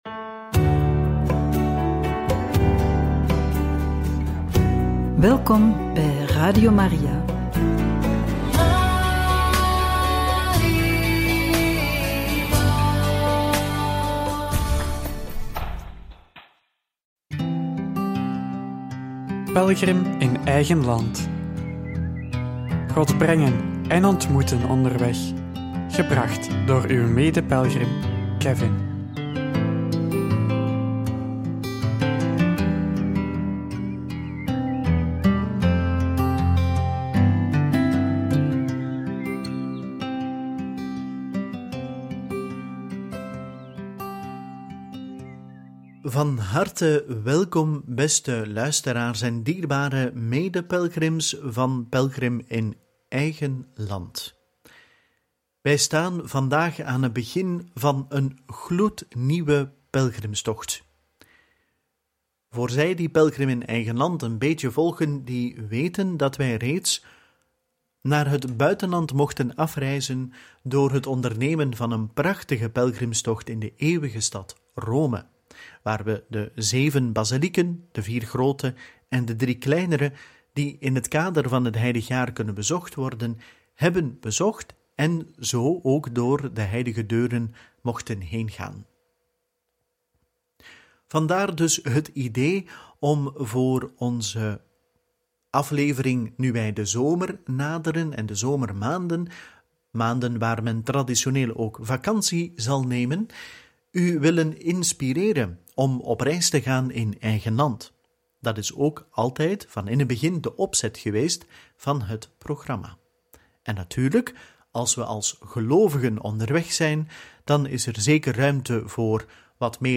Ontdek de Belgische kust met de Kusttram – De Panne – De Paterskerk – In gesprek met een oud-commissaris – Radio Maria